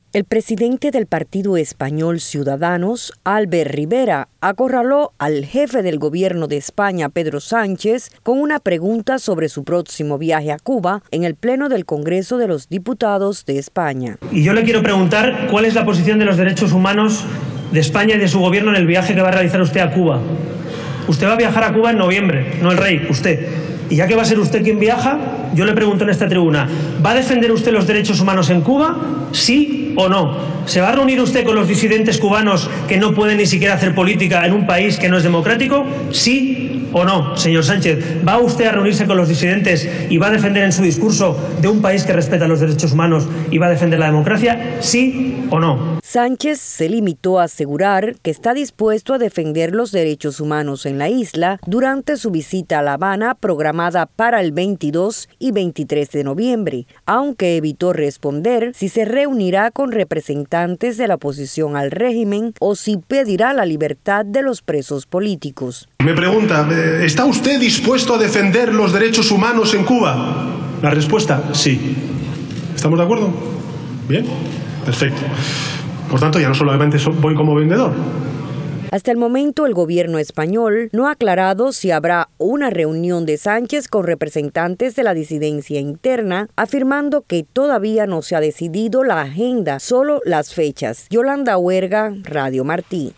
El presidente del gobierno español debió responder ante el pleno del Congreso de los Diputados cuál es la posición sobre los derechos humanos de España y de su Gobierno en el viaje que va a realizar a Cuba.
Diputado español Albert Rivera increpa a Pedro Sánchez sobre visita a Cuba